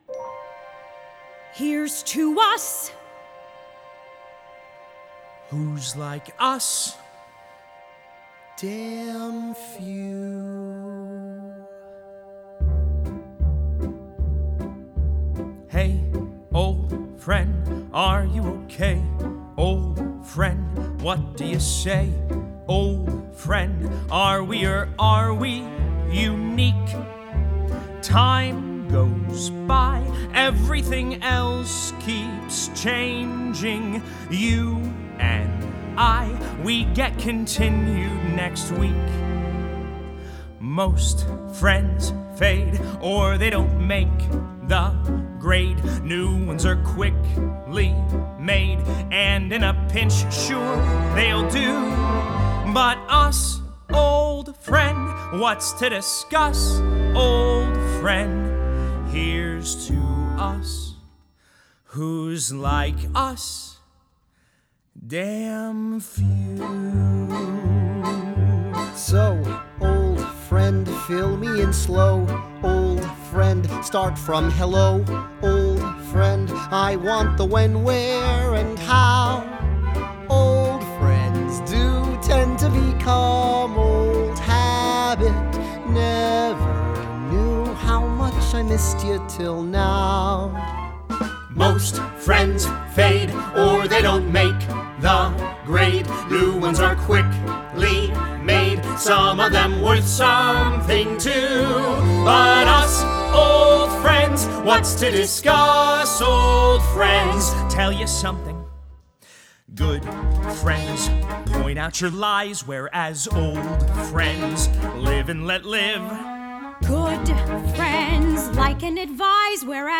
Genre: Musical